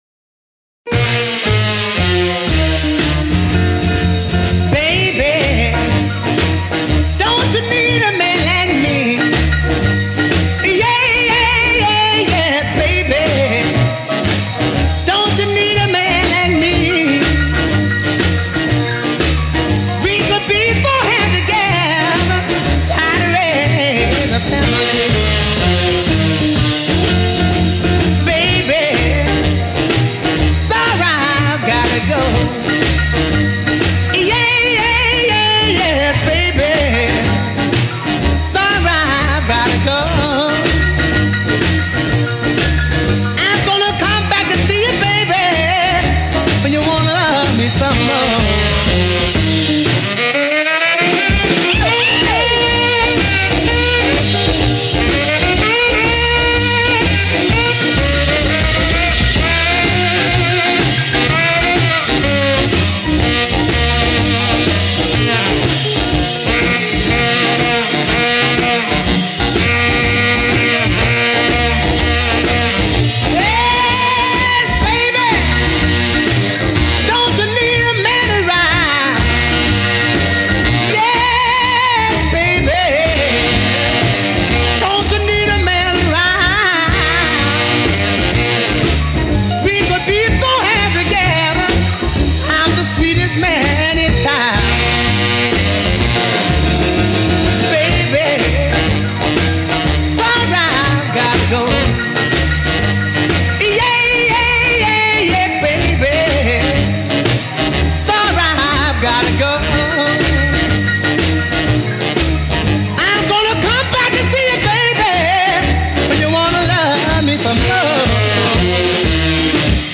Рок-н-ролл